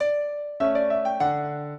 piano
minuet11-3.wav